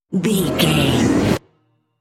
Sound Effects
Atonal
Fast
ominous
eerie
synthesiser